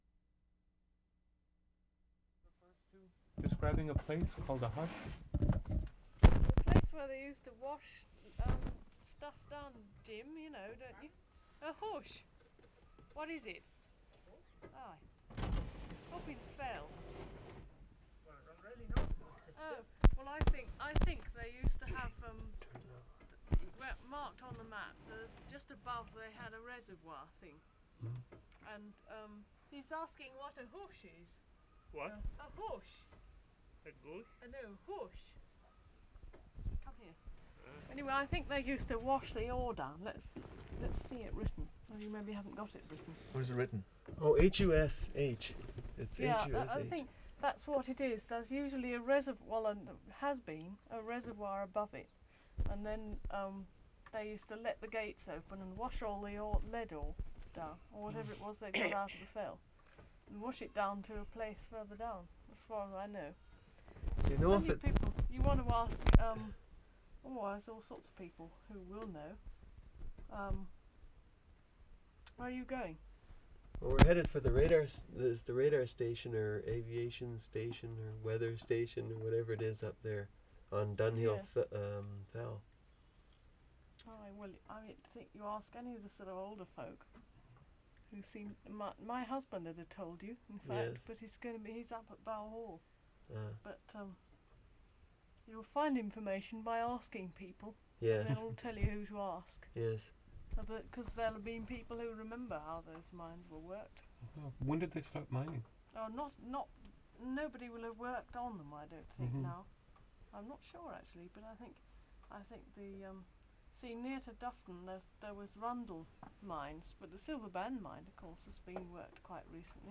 Wensleydale, England May 15/75
6. Note timbral differences between interior voices of recordists and exterior voice of woman by the road. Note also the quiet ambience.